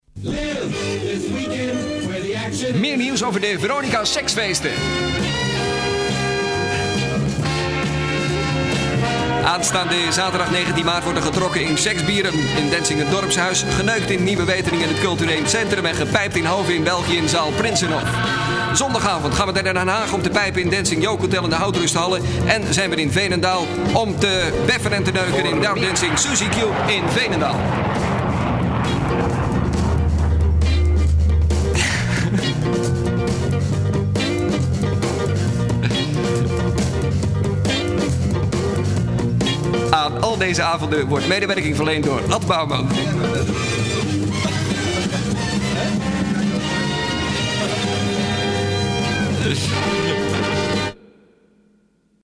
In onze archieven zitten nogal wat voorbeelden en laten we deze er uit halen en het stof er vanaf blazen: Lex deed in de begindagen van de Veronica Omroep Organisatie de spots voor de lucratieve drive in show en maakte een ondeugdende versie. Laten we zeggen dat de tekst in de spot het gedachtengoed van de gemiddelde dj bijzonder goed reflecteert.